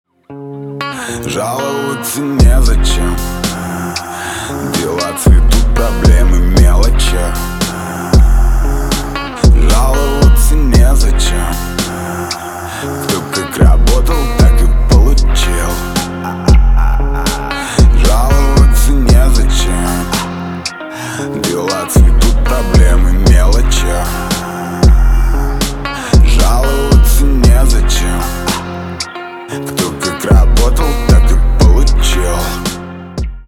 русский рэп , битовые , басы
гитара